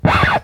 record_scratch
old record scrape scratch vinyl sound effect free sound royalty free Music